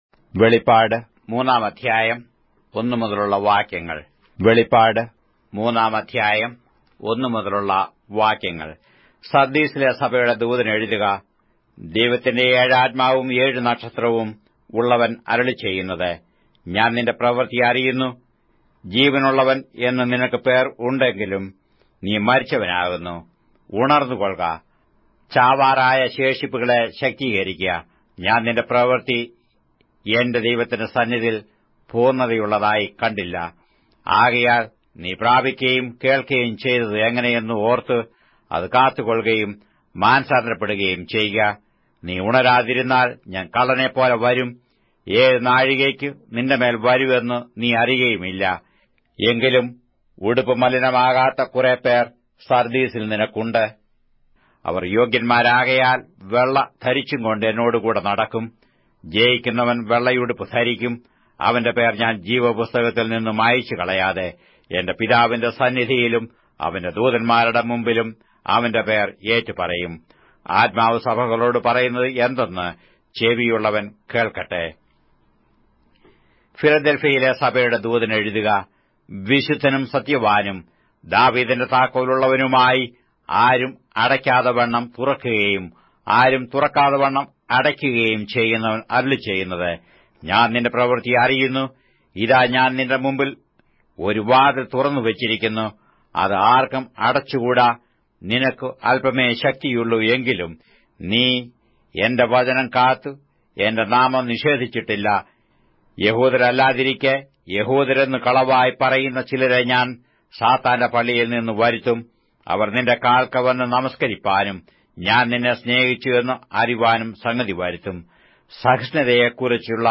Malayalam Audio Bible - Revelation 14 in Ervbn bible version